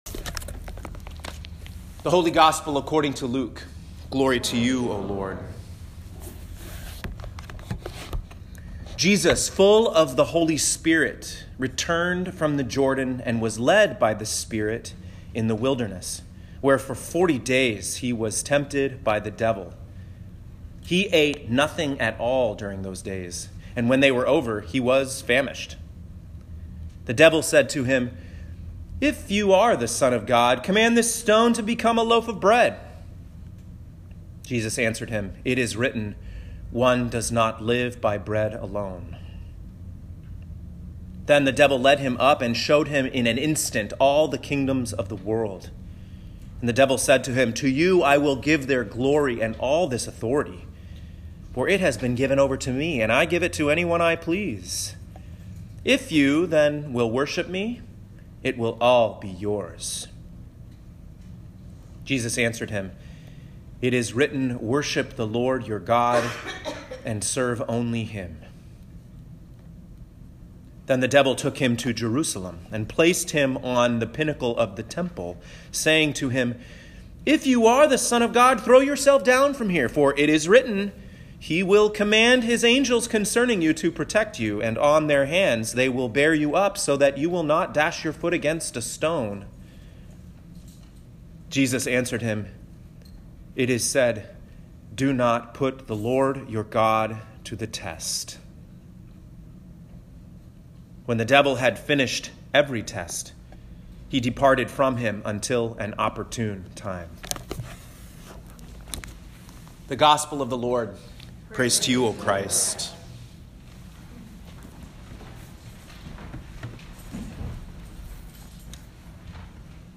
First Sunday in Lent, Year C (3/10/2019)
Home › Sermons › Faith and Superstition